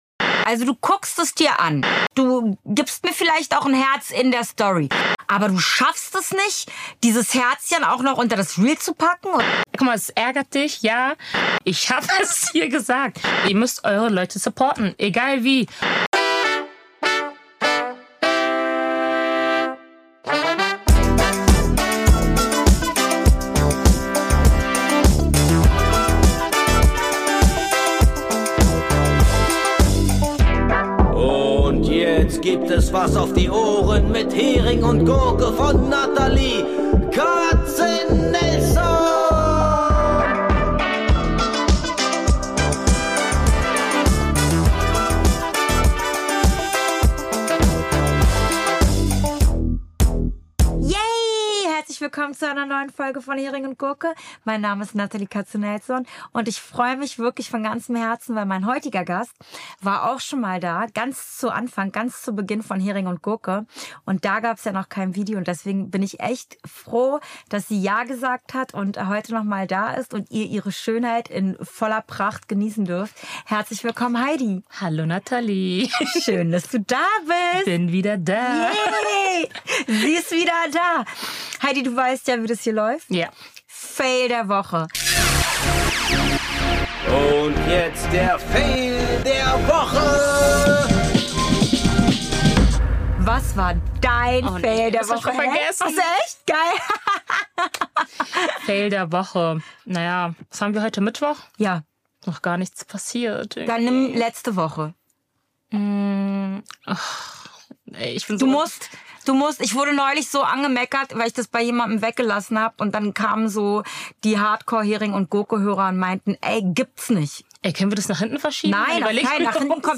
Wir steigen direkt ein: Updates, ehrliche Momente und die kleinen Spitzen, die nur unter Freunden fallen. Ein Gespräch, das ohne Umwege funktioniert — klar, warm und auf den Punkt.